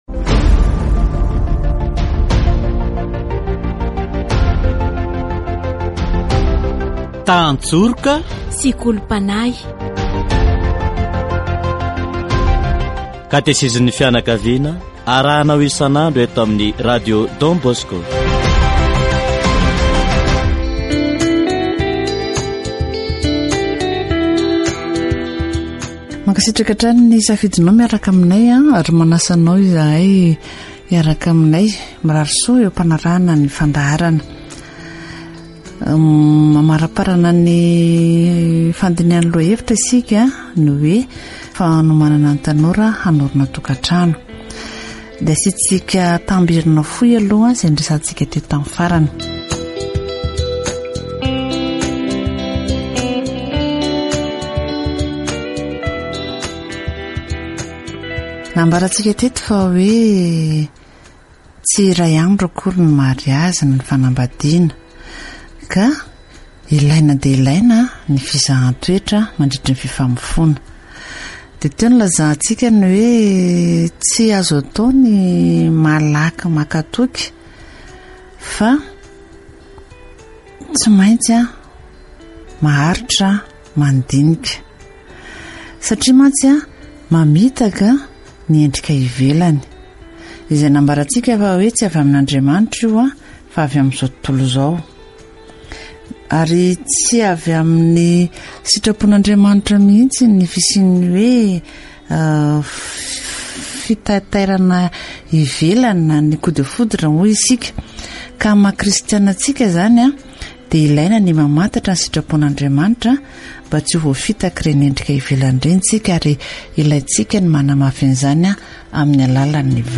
Les problèmes culturels peuvent lentement détruire une relation. Catéchèse concernant les jeunes se préparant au mariage